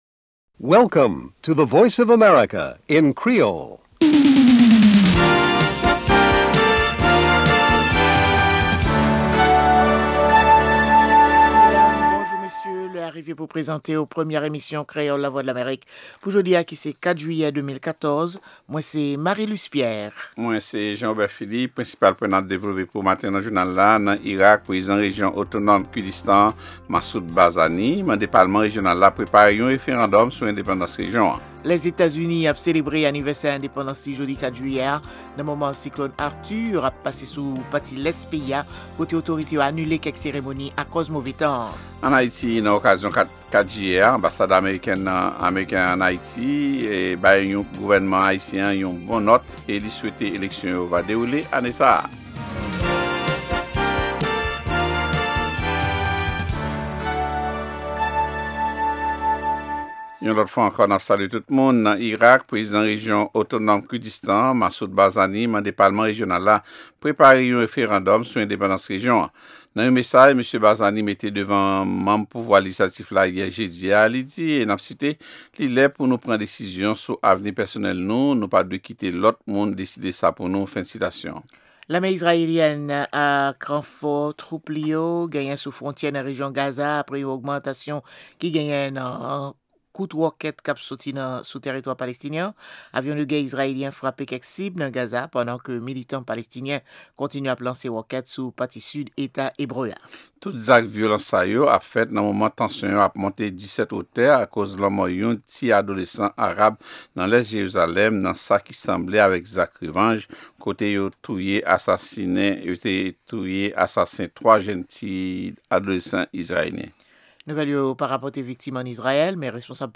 Se premye pwogram jounen an ki gen ladan dènye nouvèl sou Lèzetazini, Ayiti ak rès mond la. Pami segman yo genyen espò, dyasporama ak editoryal la.